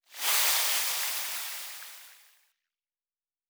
Nature Spell 29.wav